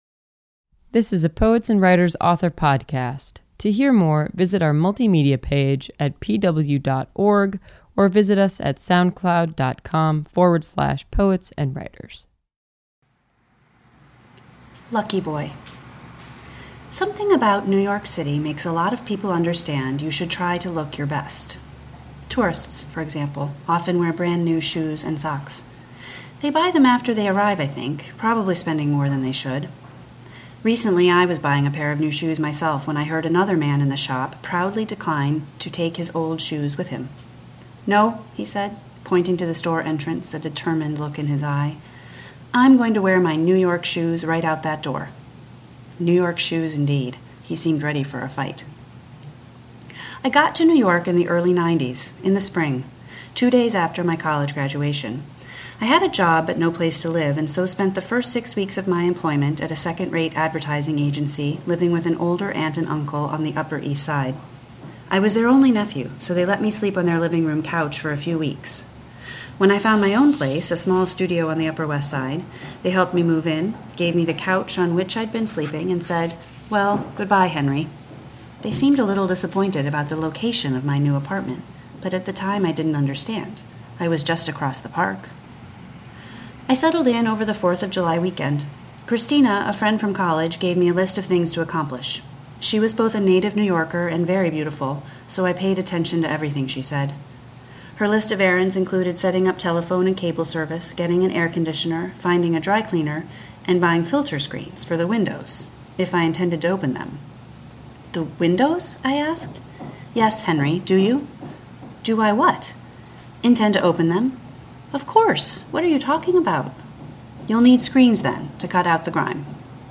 Jessica Francis Kane reads from her short story collection, This Close, published in March by Graywolf Press.